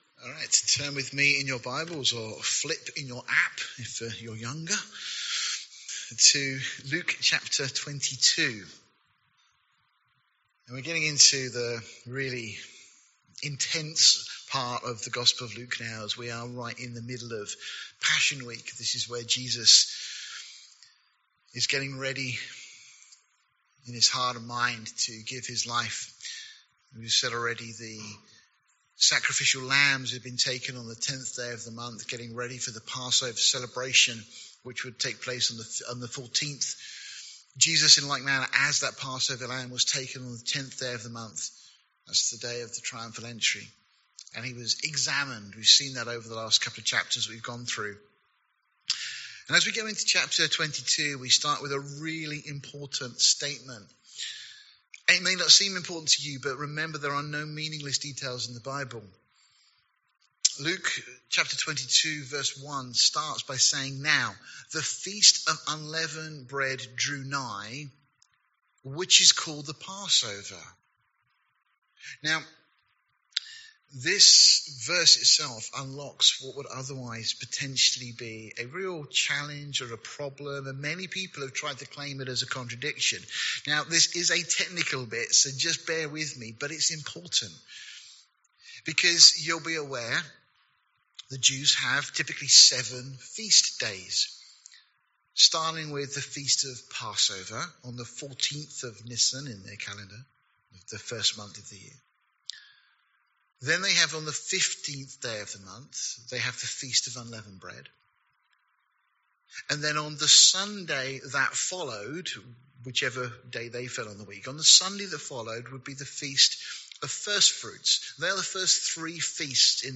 This study of Luke chapter 22
at Calvary Portsmouth.